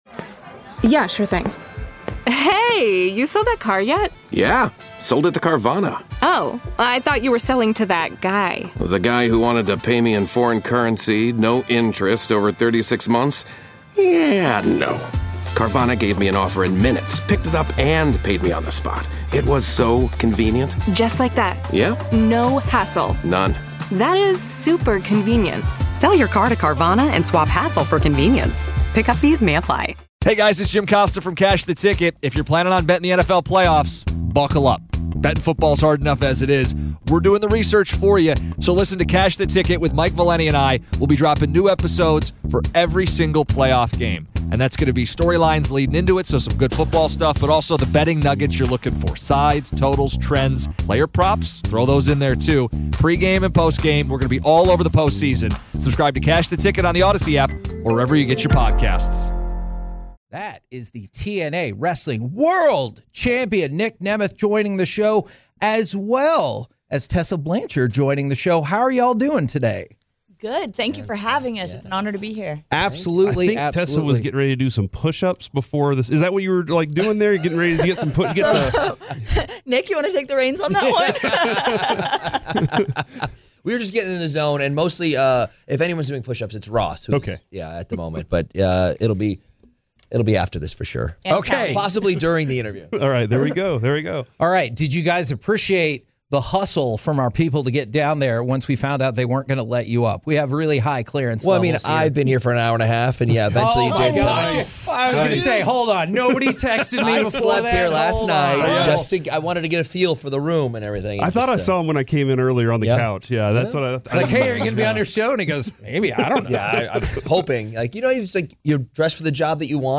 The TNA World Champion Nic Nemeth and former TNA Women's World Champion Tessa Blanchard join the guys in-studio to discuss TNA Genesis in Garland this weekend!